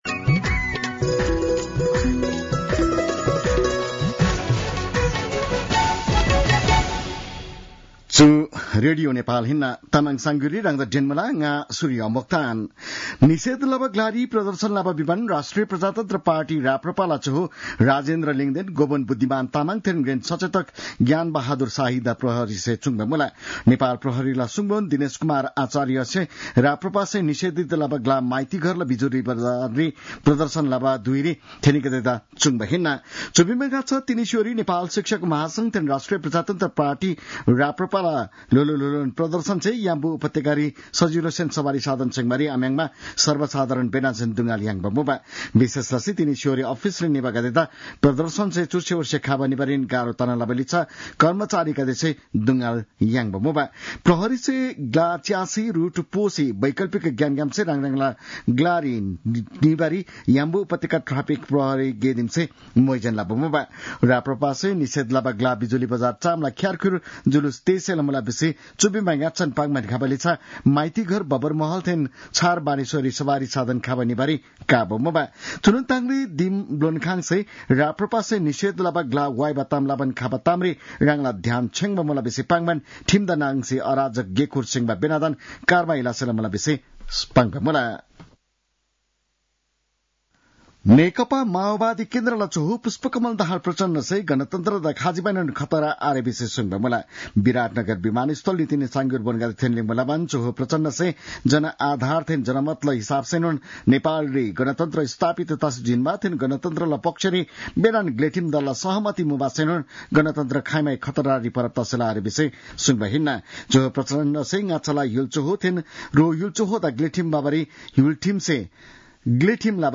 तामाङ भाषाको समाचार : ७ वैशाख , २०८२